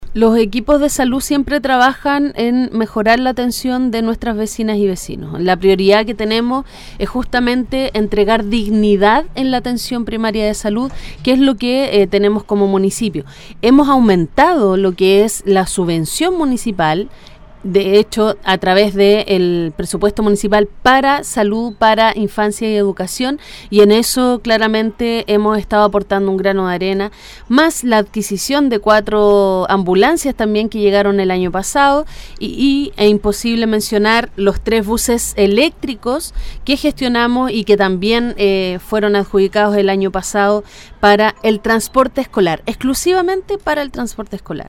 Durante una entrevista realizada en “La Mañana de Todos” de Radio Ignacio Serrano, la alcaldesa Olavarría respondió a las dudas  más importantes para la comuna de Melipilla